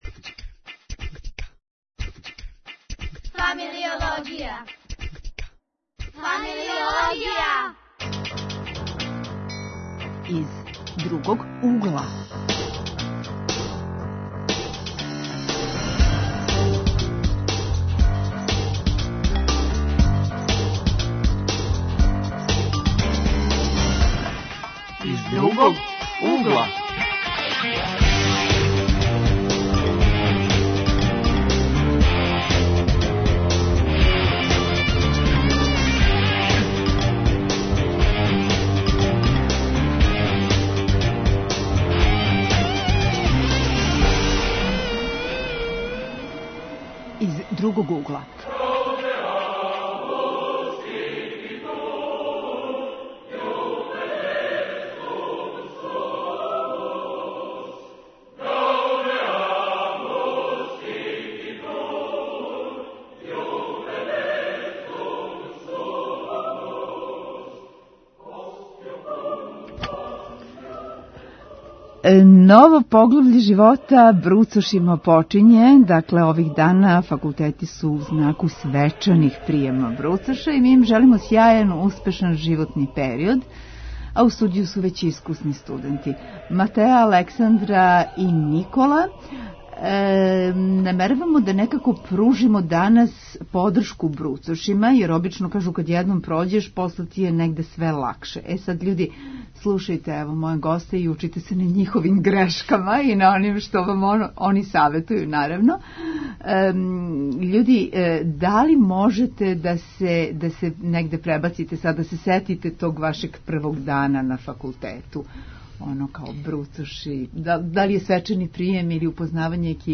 Гости - студенти Редовне рубрике: -Глас савести (искусни студенти упућују бруцошима ''Kако не поновити наше грешке?!)